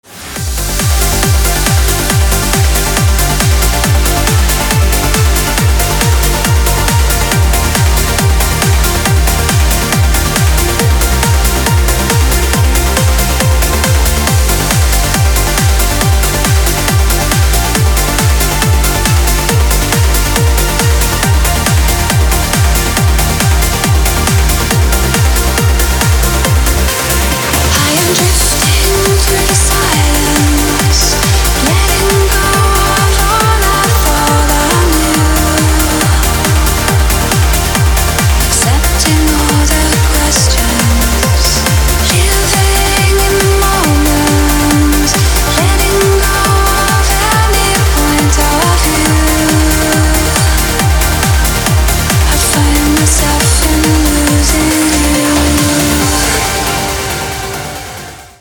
• Качество: 256, Stereo
громкие
женский вокал
dance
Electronic
EDM
электронная музыка
club
Trance
vocal trance